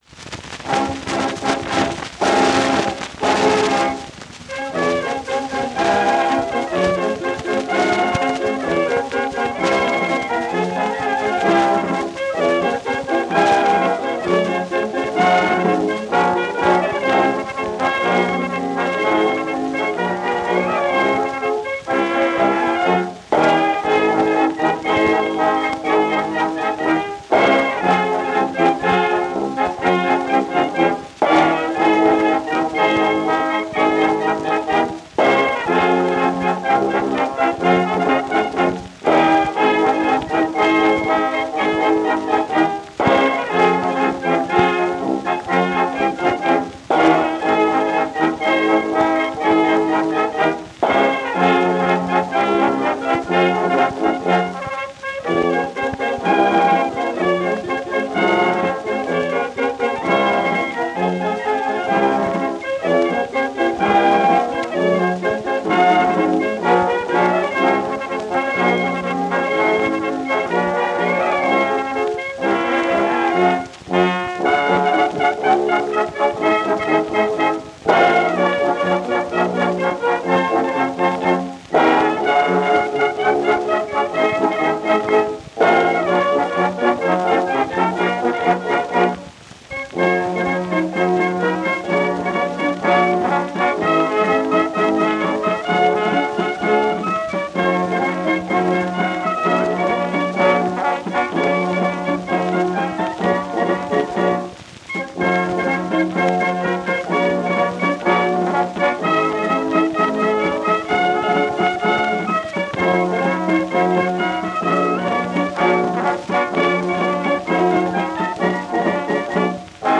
Запись сделана в 1912 году. Прошу извинить за качество звука.